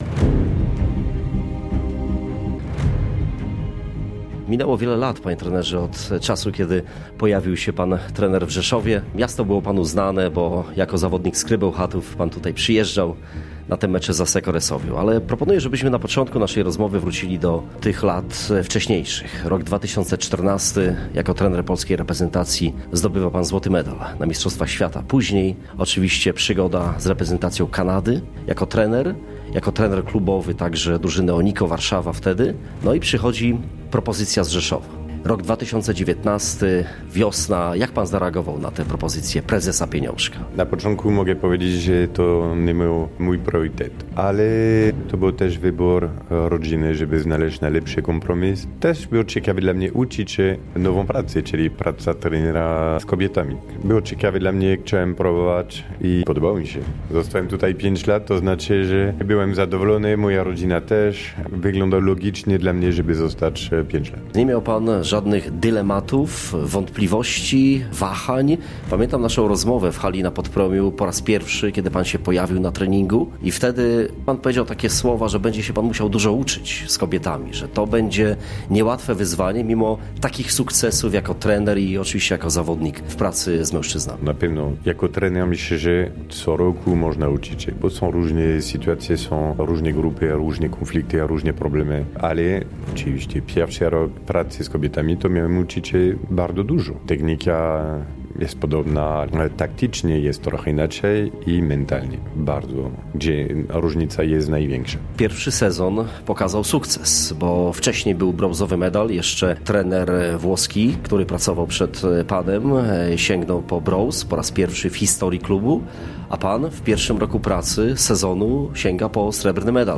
Stefan Antiga żegna się z Rzeszowem – wywiad